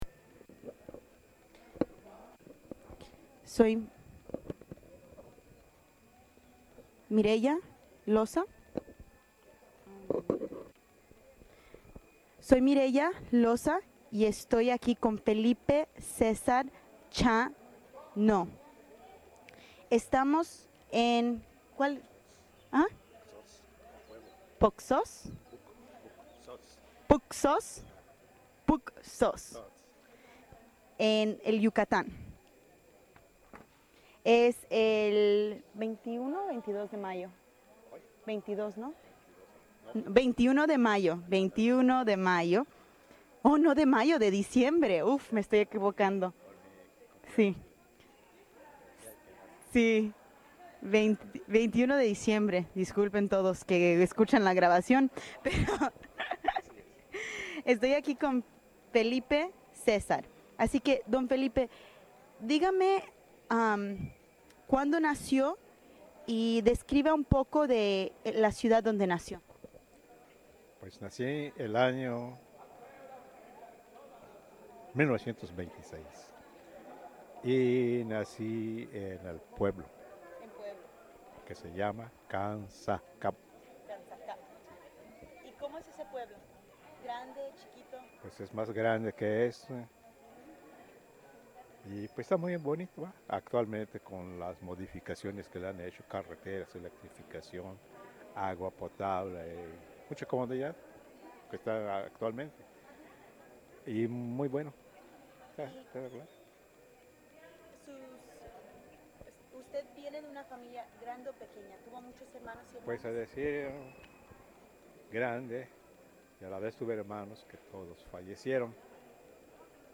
Location Dzoncauich, Yucatán Original Format Mini disc